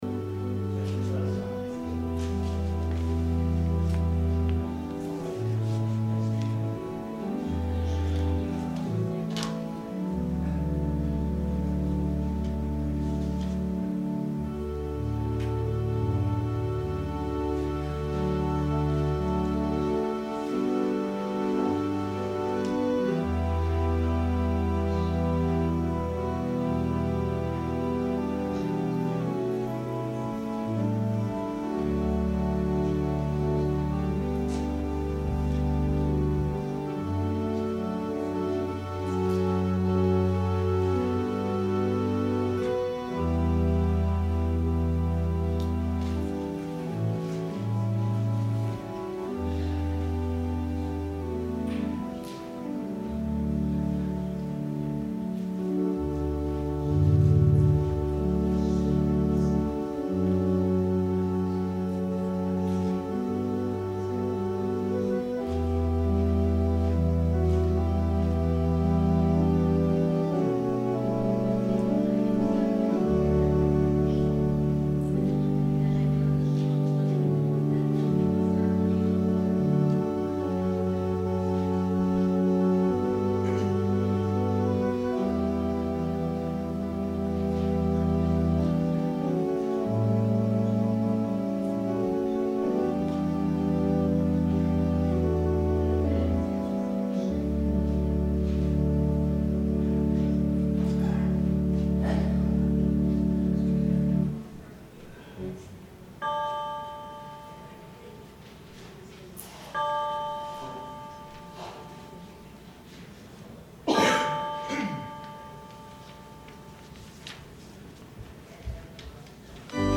Sermon – October 14, 2018